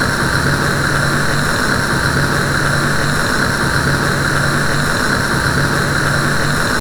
sounds / ambient / gas_lamp / on_idle.ogg